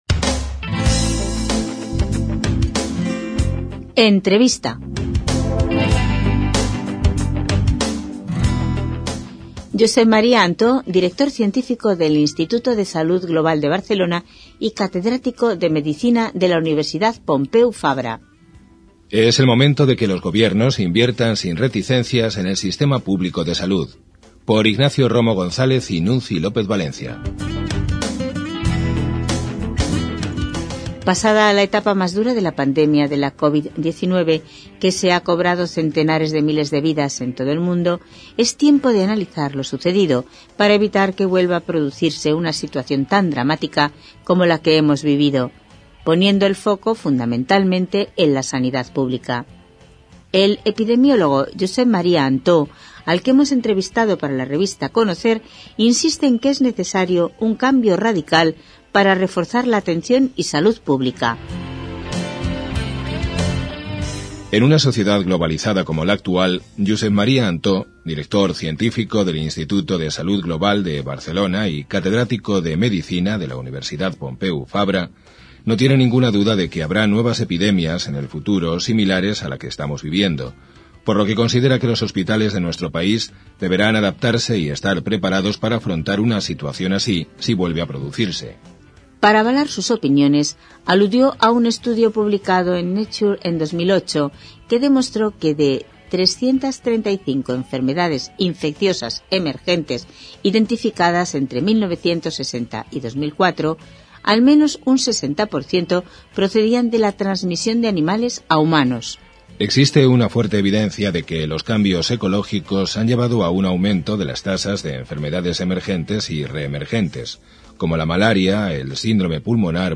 06 ENTREVISTA_18.mp3